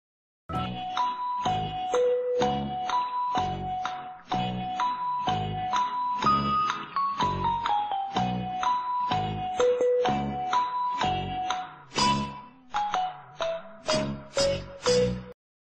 MÚSICA-DE-DUDA-Efecto-de-Sonido.mp3
LDfoA7L0TGa_MÚSICA-DE-DUDA-Efecto-de-Sonido.mp3